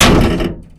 car_heavy_2.wav